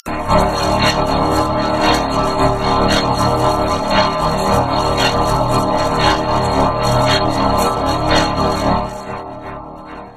Failed Vocoder Effect Botón de Sonido